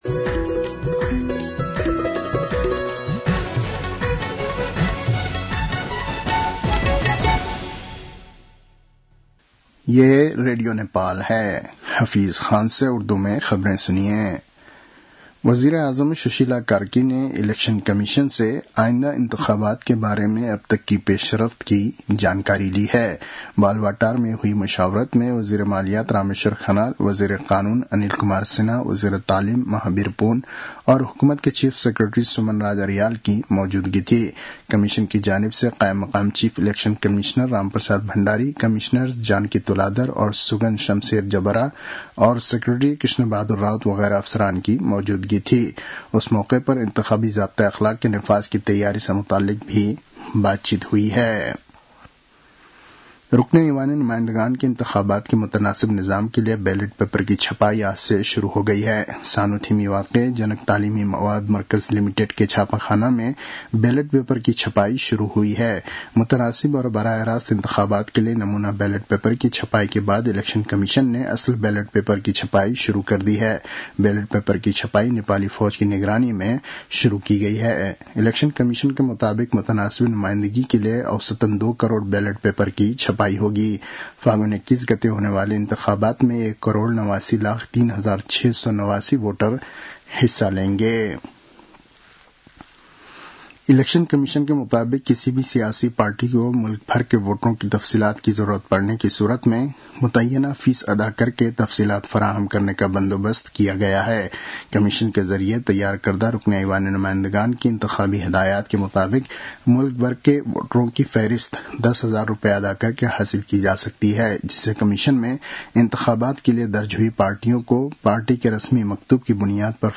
उर्दु भाषामा समाचार : २५ पुष , २०८२